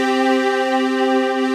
corppad.wav